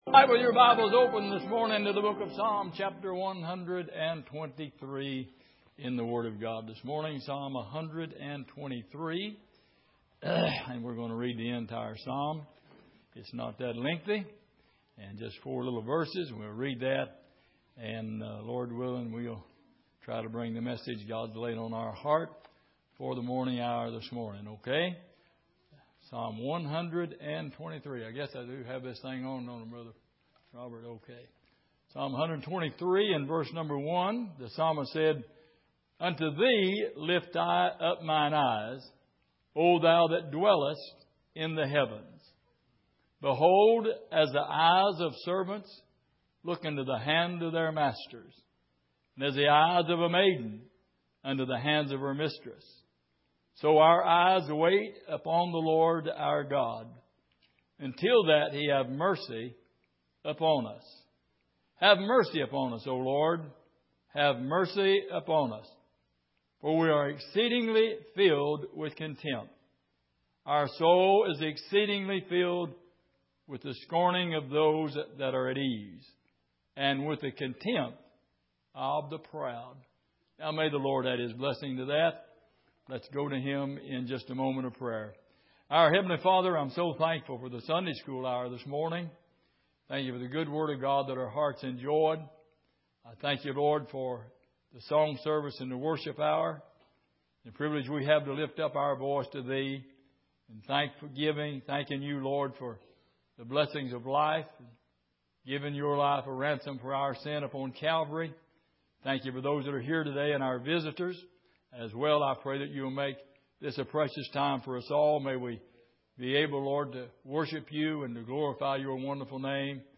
Exposition of the Psalms Passage: Psalm 123:1-4 Service: Sunday Morning What Are You Hoping To See?